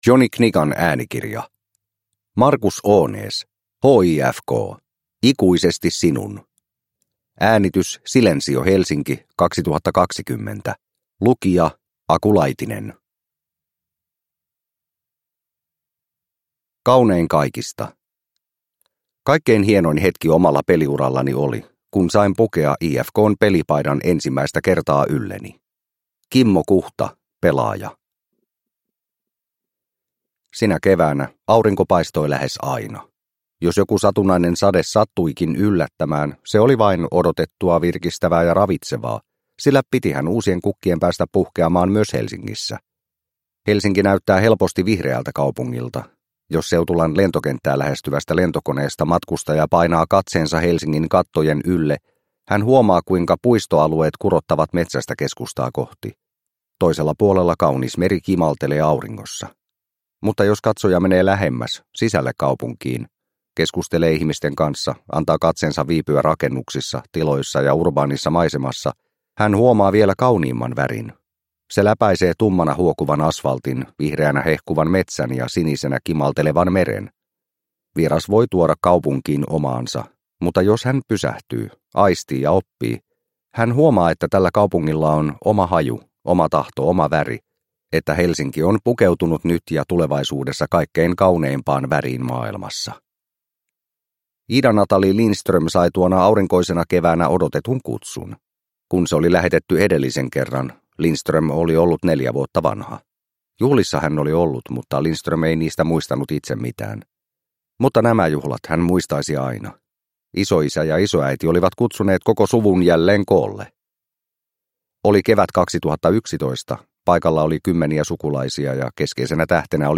HIFK - Ikuisesti sinun – Ljudbok – Laddas ner